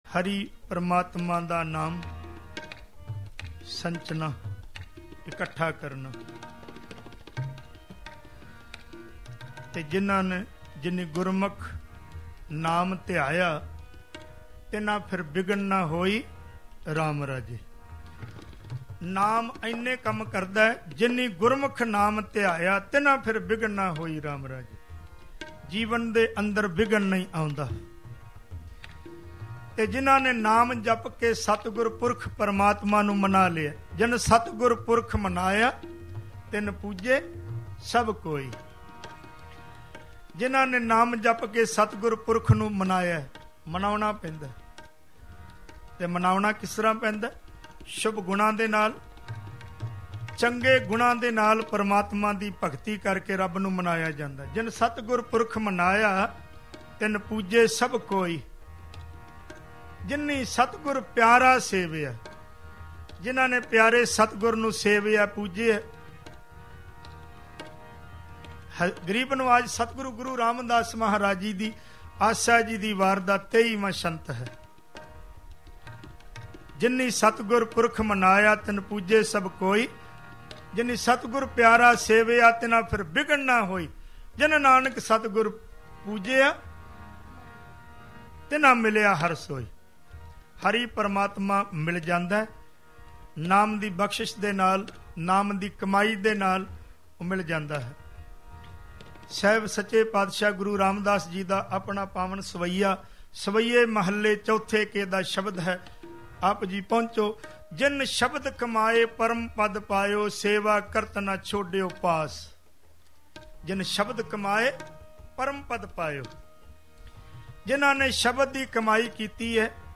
Shabad Gurbani Kirtan Album Info